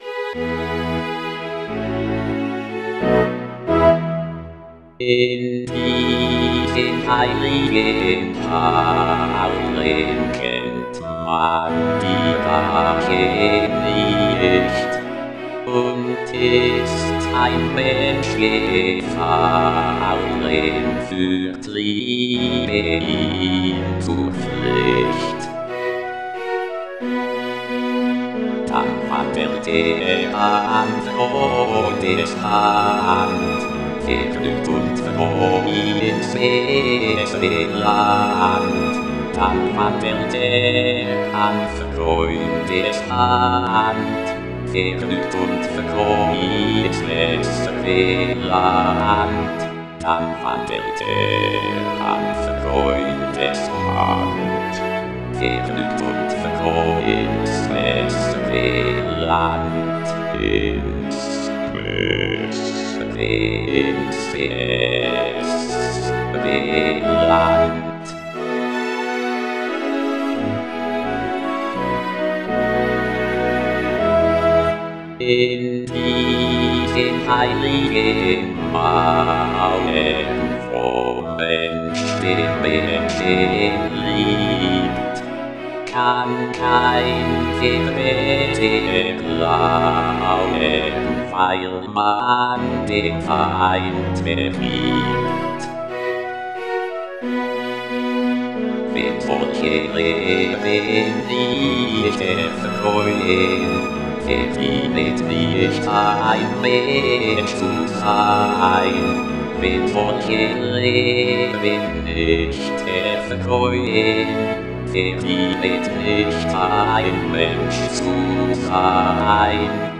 Both are speech synthesizers singing in a language that is not English.